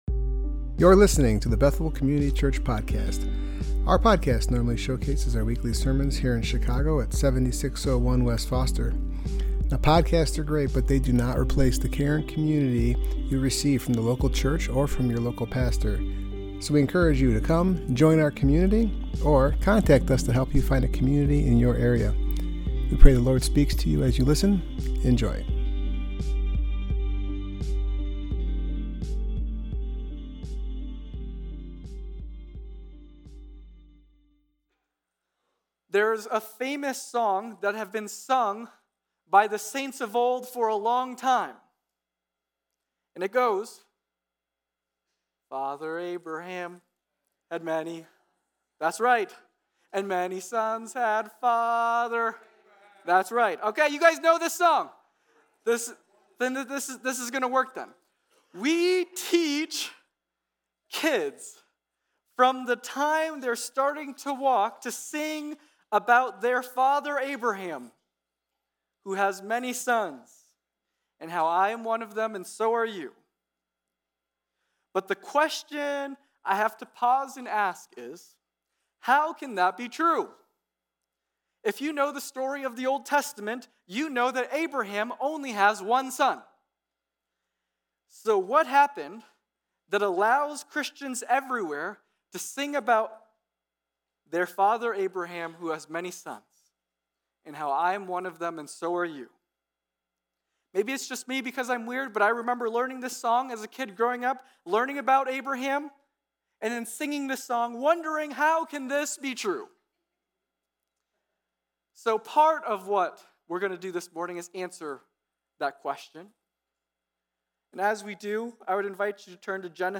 Service Type: Worship Gathering